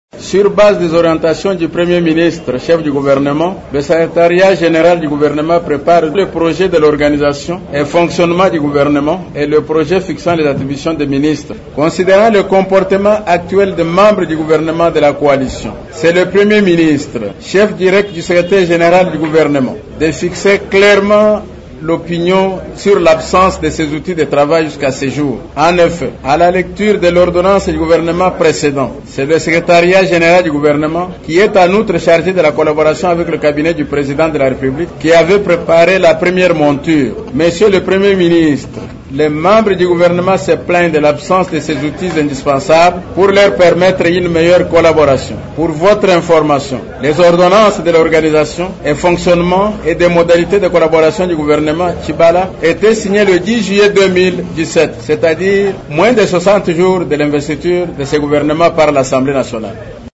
Dans une déclaration dimanche 09 février à la presse à Kinshasa